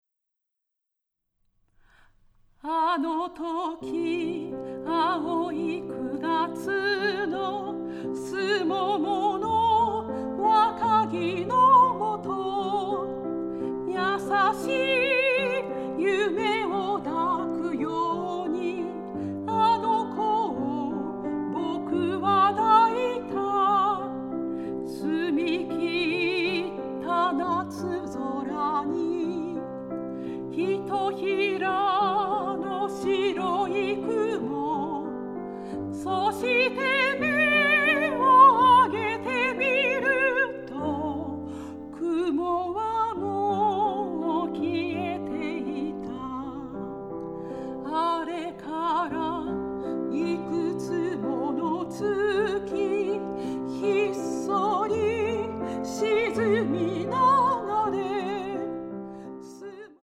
ヒリヒリとした緊張感と童謡のように優しく歌に包まれる感覚が同居していて◎！